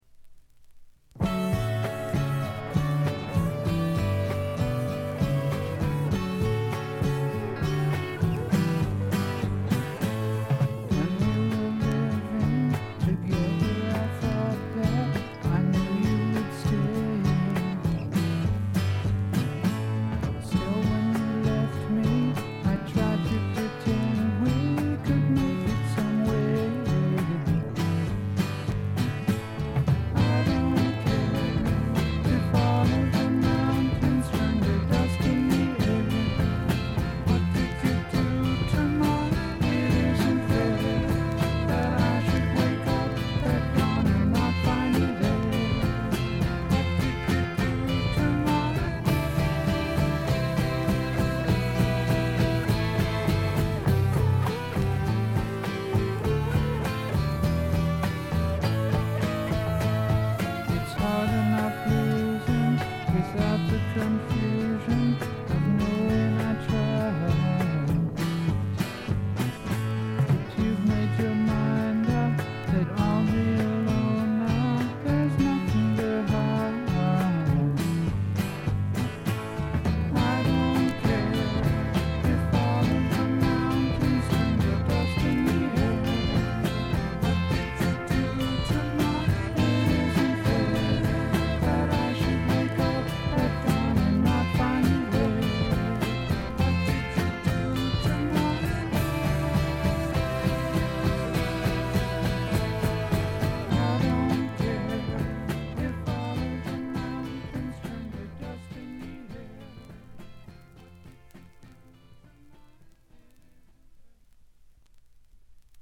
静音部ところどころでチリプチ。散発的なプツ音少々。
試聴曲は現品からの取り込み音源です。